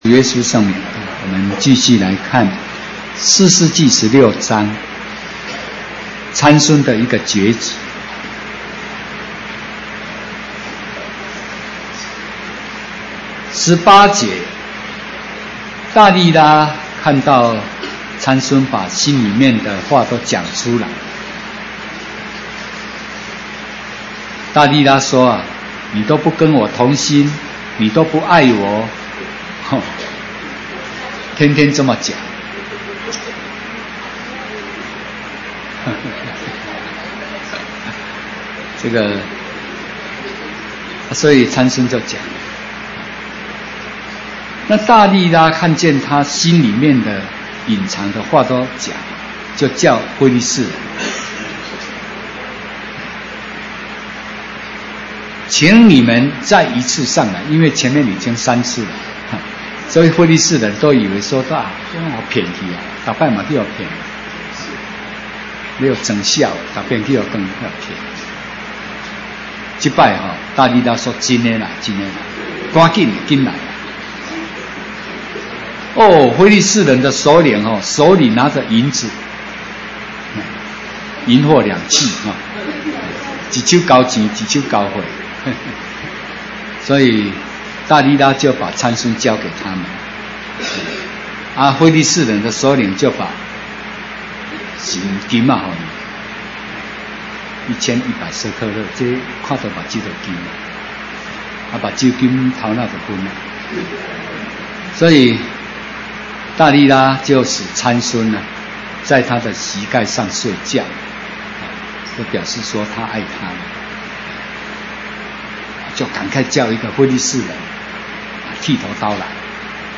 講習會
地點 台灣總會 日期 02/14/2016 檔案下載 列印本頁 分享好友 意見反應 Series more » • 士師記 20-1 • 士師記 20-2 • 士師記 20-3 …